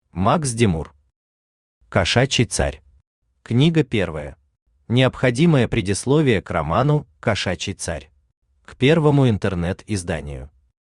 Aудиокнига Кошачий царь. Книга первая Автор Макс Димур Читает аудиокнигу Авточтец ЛитРес.